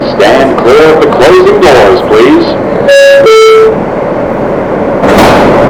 Canal St.